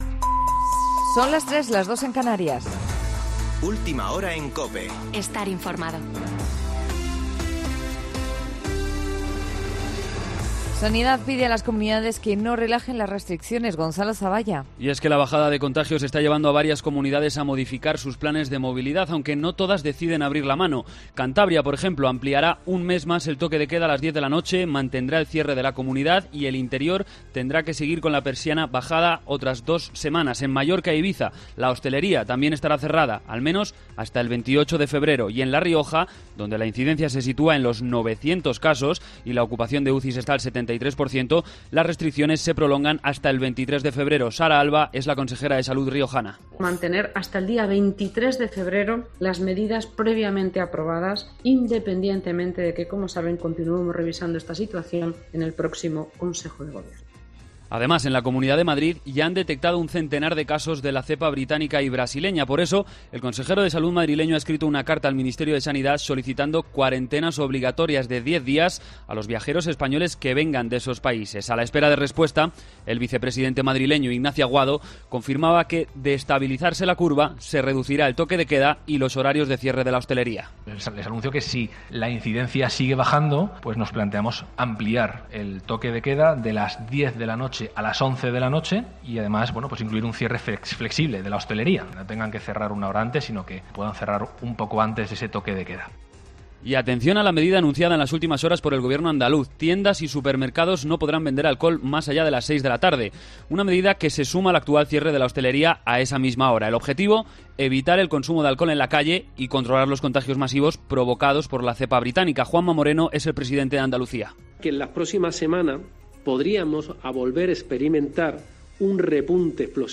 Boletín de noticias COPE del 11 de febrero de 2021 a las 03.00 horas